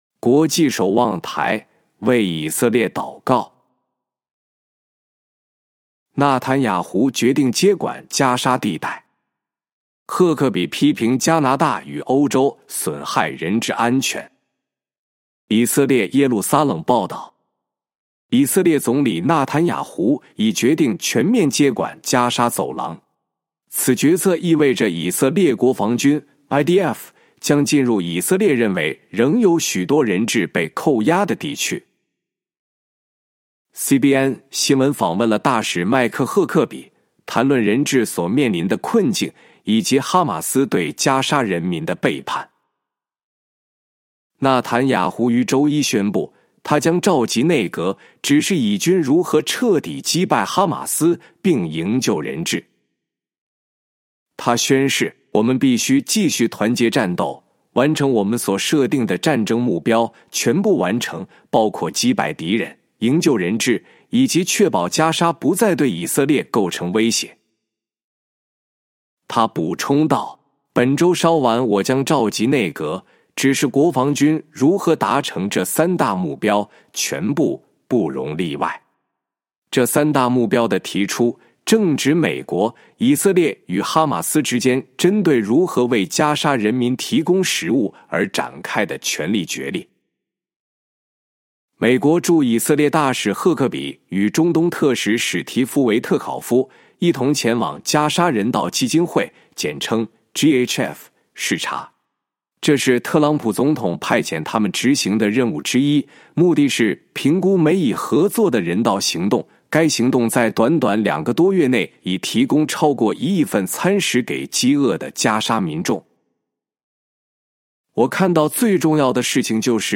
本篇是由微牧之歌翻译撰稿祷告及朗读